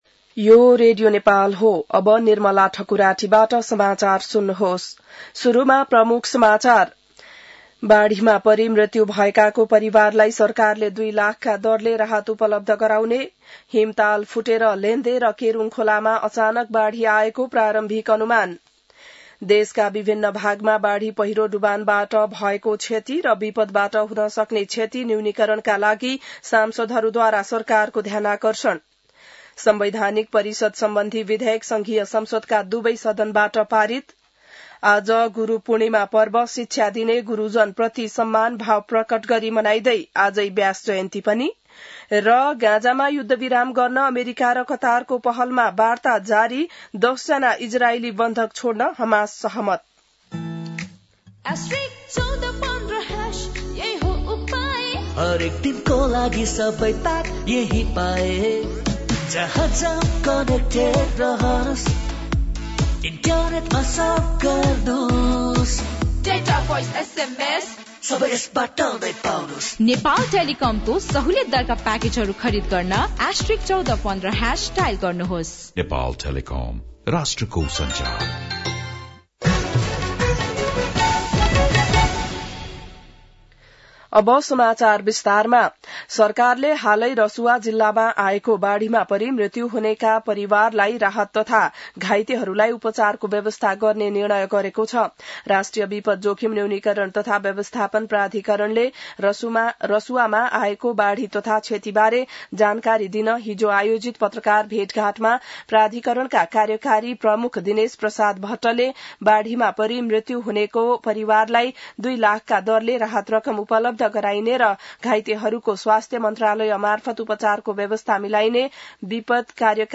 बिहान ७ बजेको नेपाली समाचार : २६ असार , २०८२